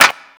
Clap
Original creative-commons licensed sounds for DJ's and music producers, recorded with high quality studio microphones.
Natural Clap F# Key 23.wav
clap-f-sharp-key-25-vqo.wav